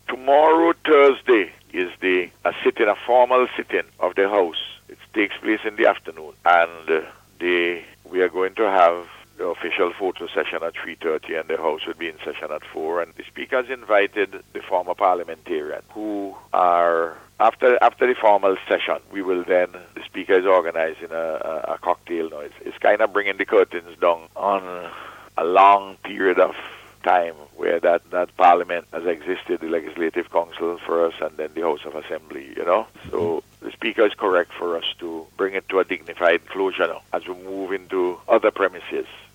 Speaking on NBC’s Face to Face programme this morning, Prime Minister Dr. Ralph Gonsalves said that former Parliamentarians have been invited to attend tomorrow’s formal sitting, which is scheduled to begin at 4:00pm.